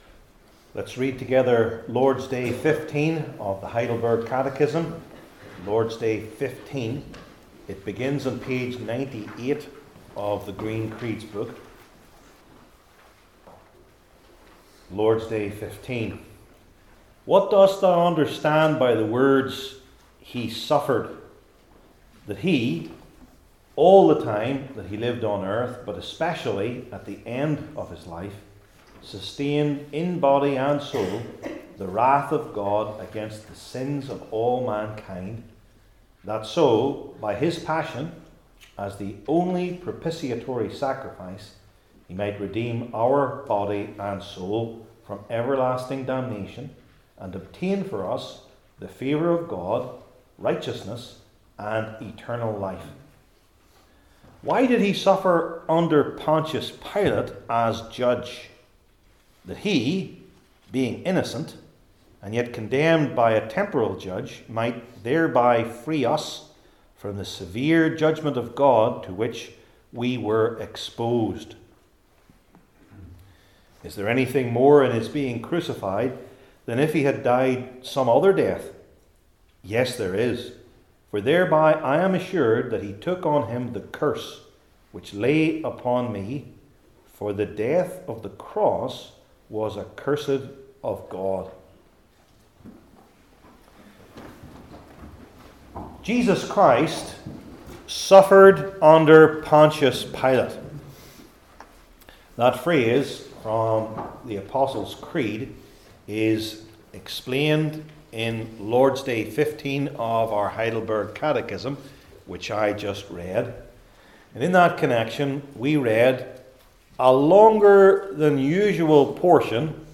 Heidelberg Catechism Sermons I. Historical II.